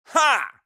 MaleGrunt2.wav